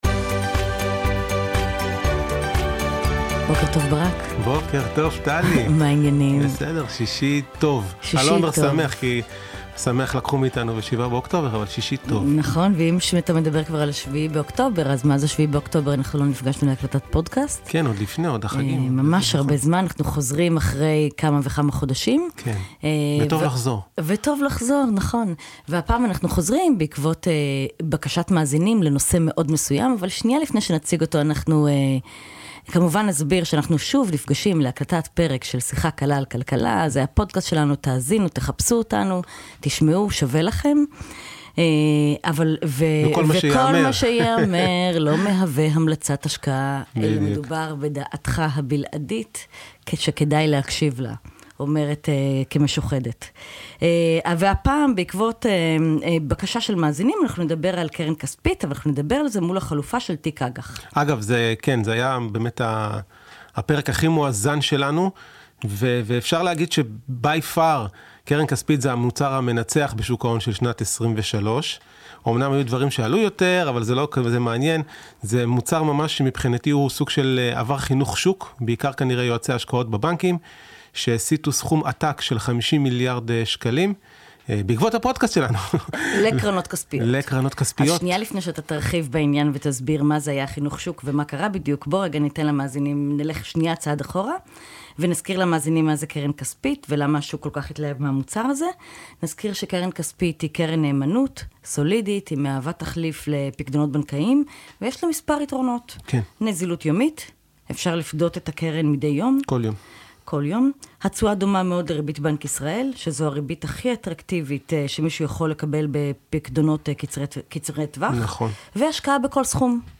משוחחים על השקעה בקרן כספית לעומת השקעה בשוק האג"ח על רקע הצפי להפחתת ריבית בישראל ומסבירים יתרונות וחסרונות